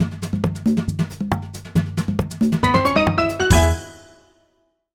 This is a sample from a copyrighted musical recording.